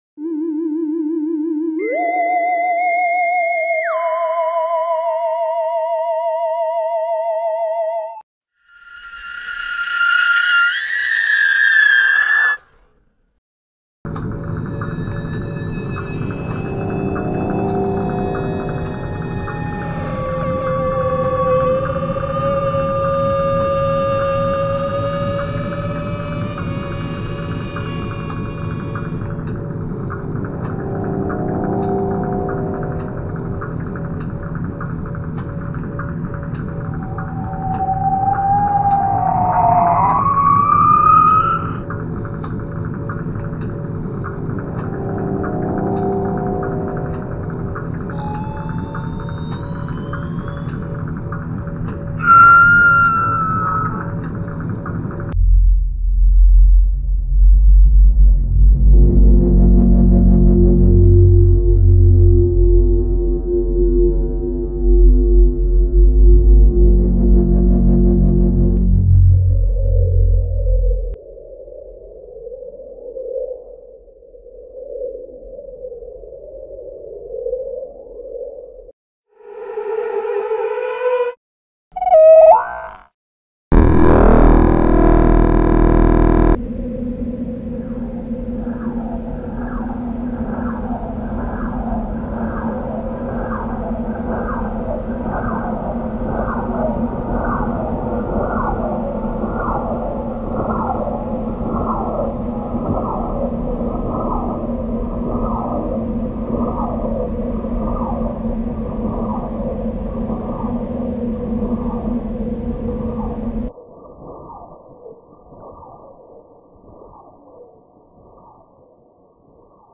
دانلود آهنگ آدم فضایی 2 از افکت صوتی انسان و موجودات زنده
جلوه های صوتی
دانلود صدای آدم فضایی 2 از ساعد نیوز با لینک مستقیم و کیفیت بالا